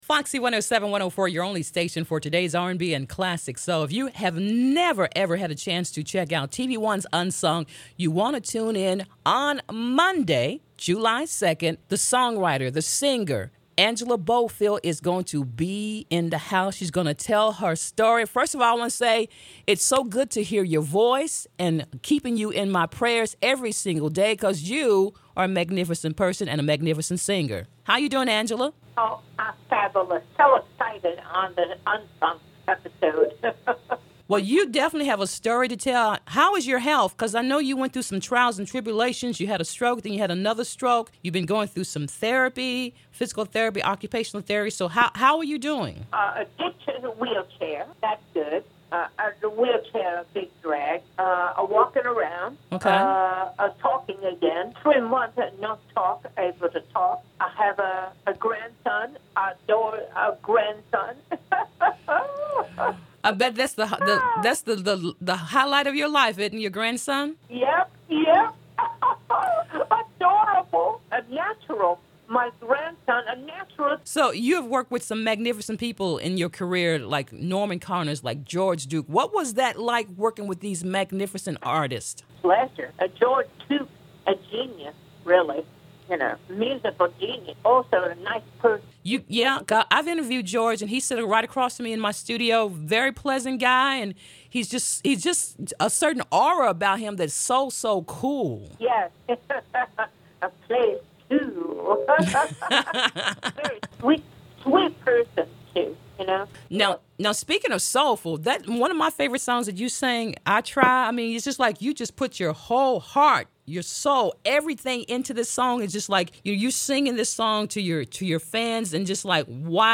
I talked with her about her career, her music and the many influences in her life and the massive stroke she suffered. She was witty and full of good humor.